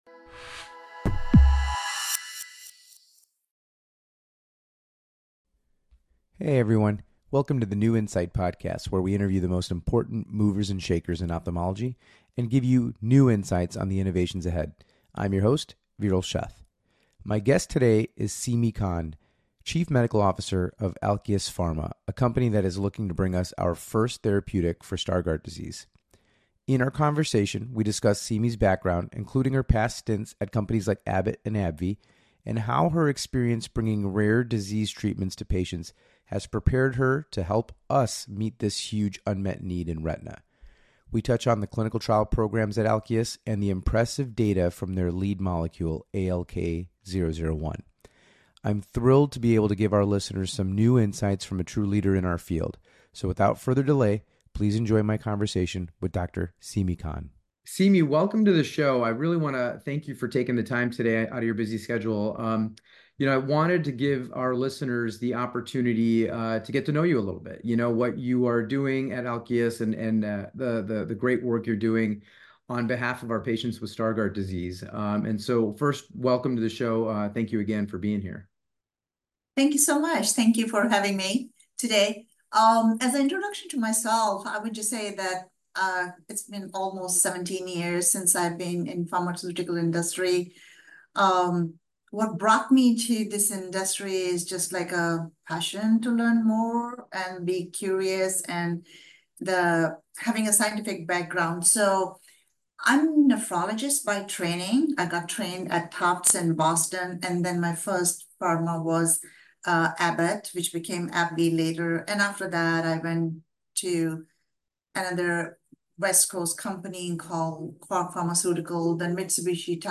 After a historically low-transmission summer, new cases, hospitalizations and deaths due to COVID-19 have increased by approximately 18% in the US over the last week, according to CDC data. In a new DocTalk interview